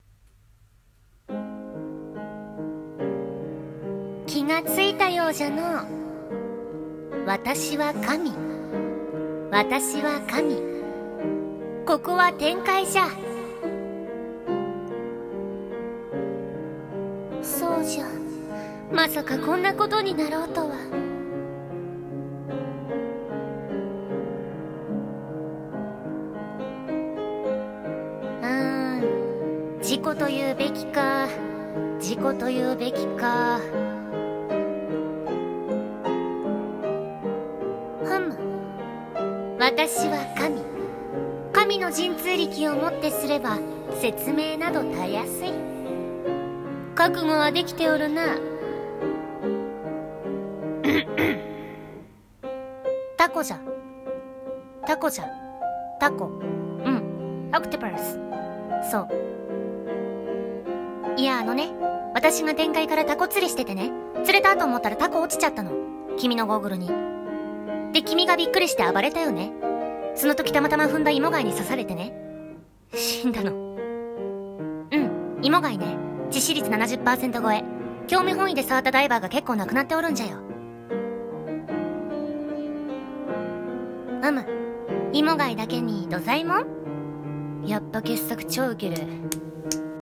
【ギャグ声劇】突然の死（前編）【掛け合い】